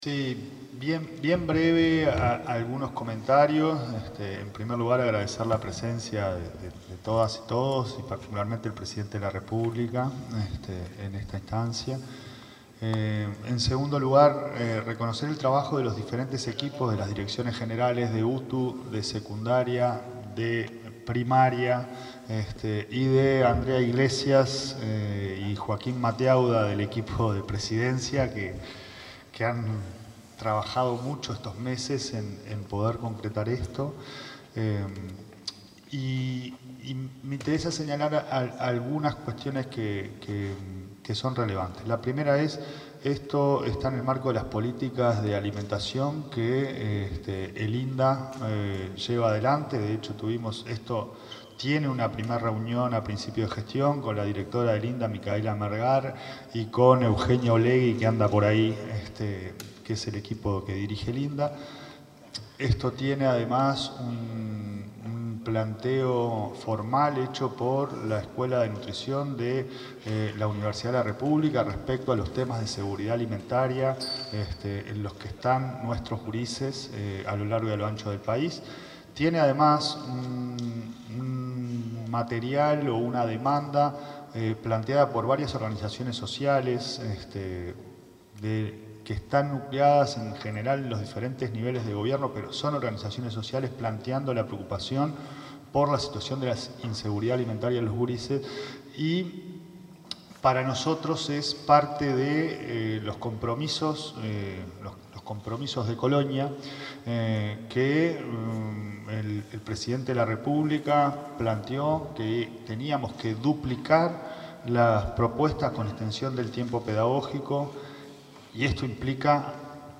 Palabras del presidente de ANEP, Pablo Caggiani
El presidente de la Administración Nacional de Educación Pública (ANEP), Pablo Caggiani, se expresó en la ceremonia de presentación del Programa de Alimentación en Educación Media, que permitirá duplicar la cobertura de estudiantes, de 20.000 a 40.000.